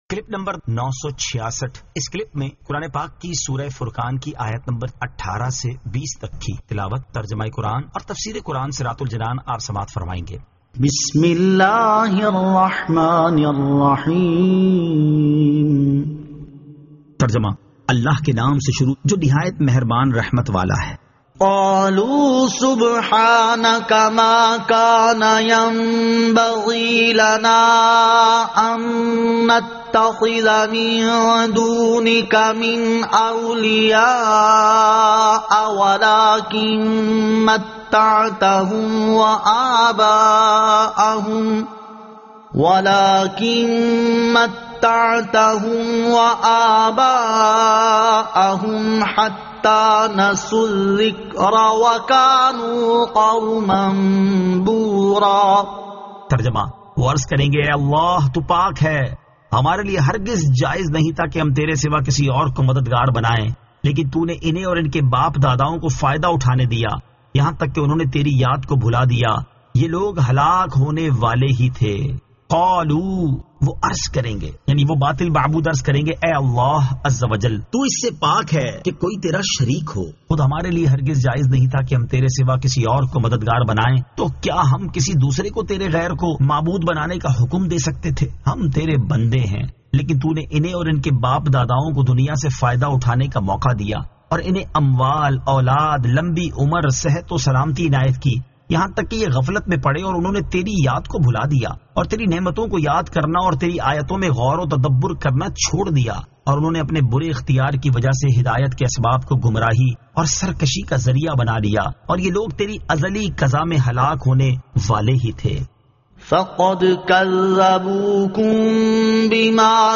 Surah Al-Furqan 18 To 20 Tilawat , Tarjama , Tafseer